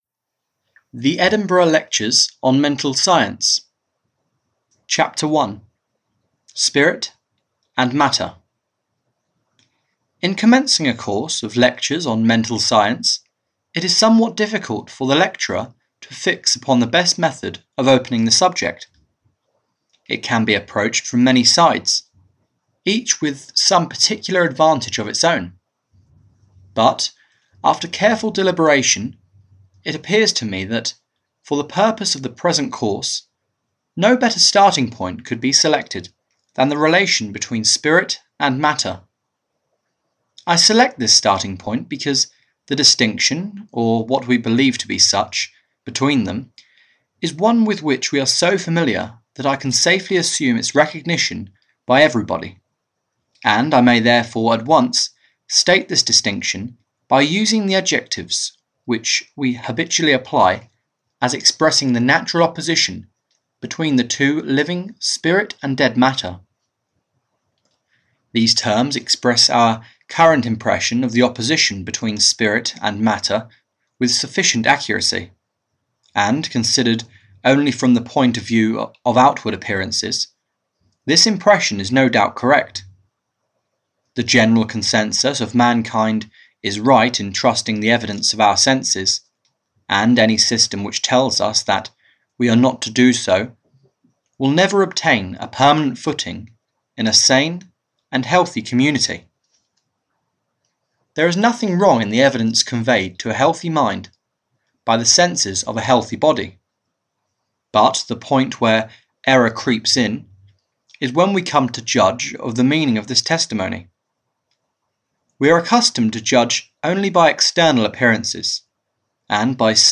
Audio knihaThe Edinburgh Lectures on Mental Science (EN)
Ukázka z knihy